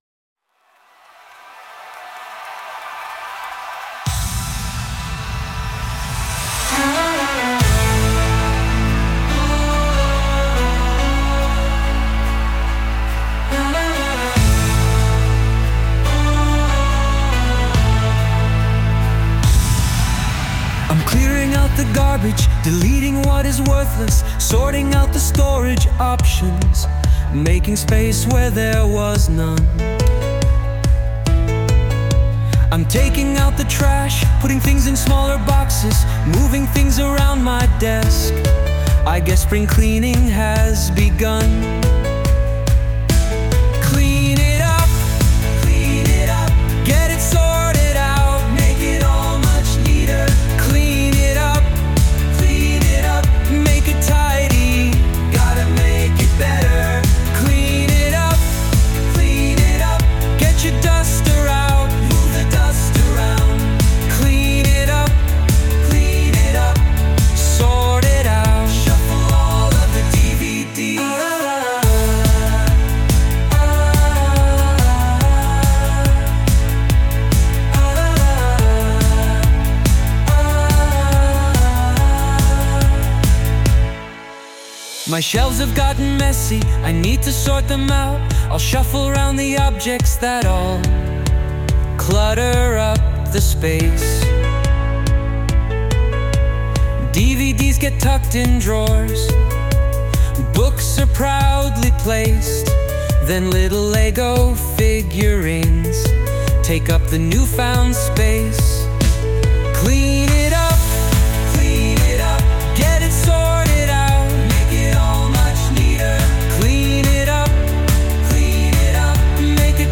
Sound Imported : Deconstructed Plumbing
Sung by Suno